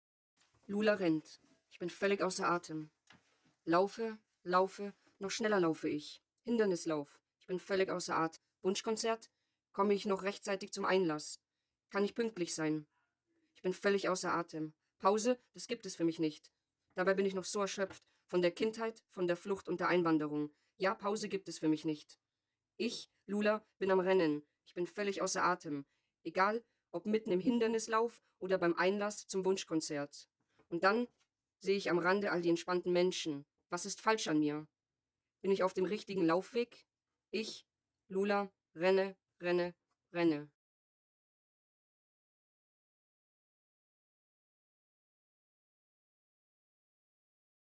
Junge Frauen aus München, mit und ohne Zuwanderungsgeschichte, beschrieben in sehr persönlichen Worten die Bedeutung von Bildung und Berufsausbildung für ihre Biografie.
Produziert wurden die O-Töne speziell für das Fachforum.